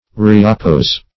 Reoppose \Re`op*pose"\ (r?`?p-p?z"), v. t. To oppose again.